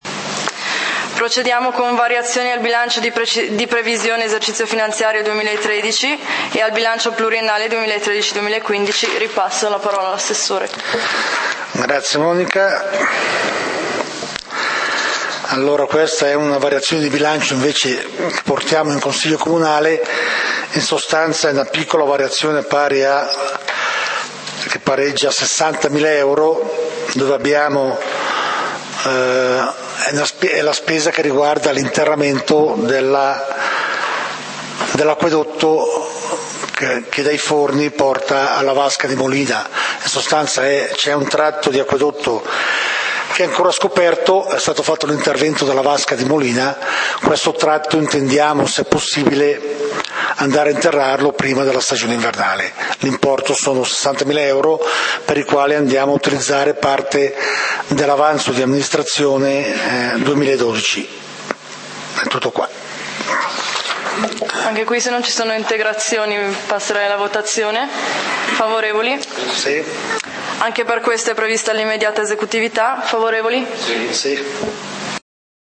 Consiglio comunale di Valdidentro del 14 Ottobre 2013